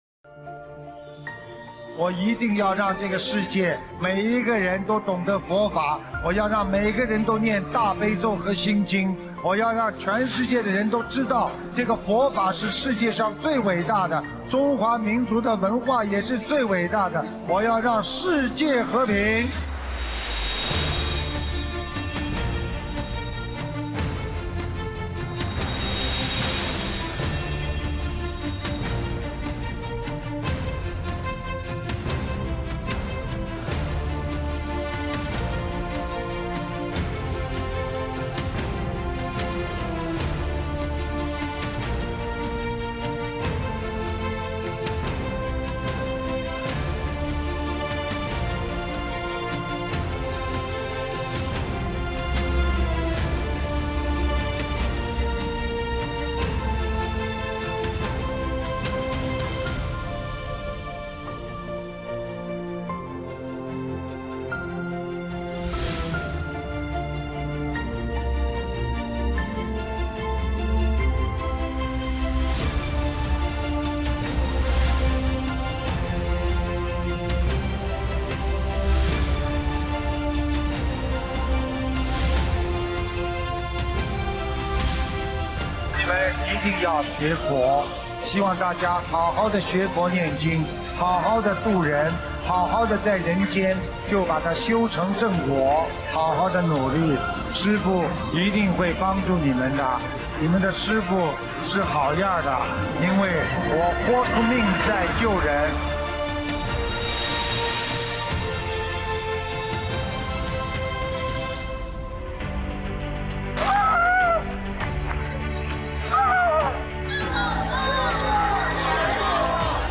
视频：23.佛學會訪談【訪談分享】06 - 新闻报道 心灵净土